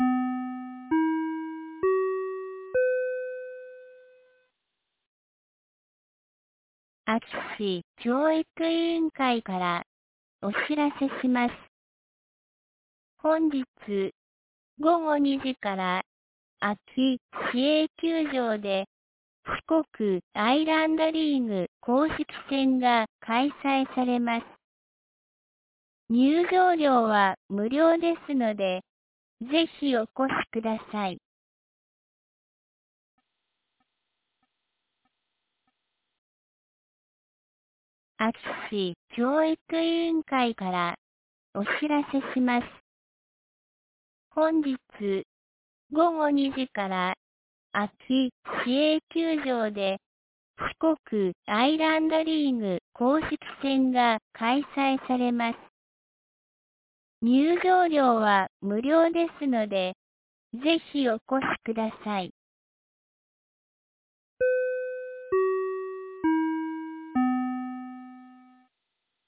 2022年09月20日 12時41分に、安芸市より全地区へ放送がありました。